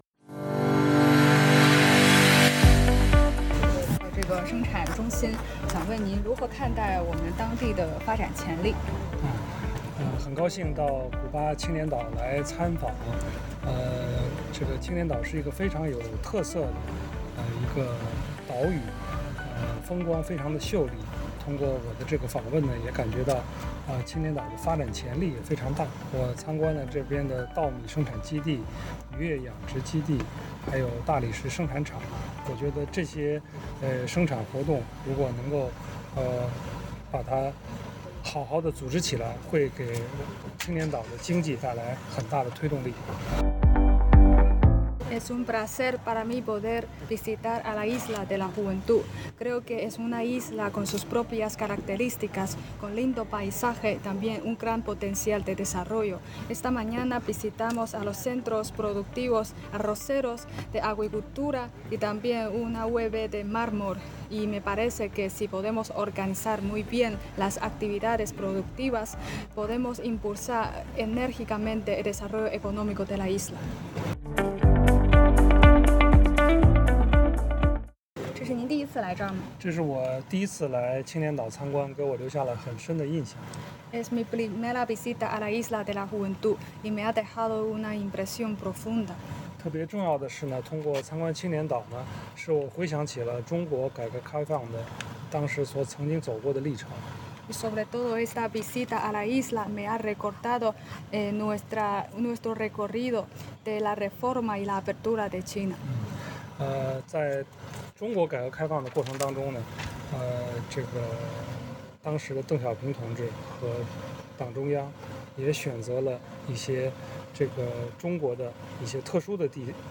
Entrevista al embajador de la República Popular China Huan Xin